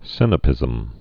(sĭnə-pĭzəm)